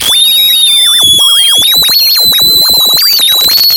描述：由MuteSynth产生的假短波无线电干扰
标签： 短波 静音合成器 假短波 干扰 无线电
声道立体声